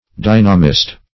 Search Result for " dynamist" : The Collaborative International Dictionary of English v.0.48: Dynamist \Dy"na*mist\, n. One who accounts for material phenomena by a theory of dynamics.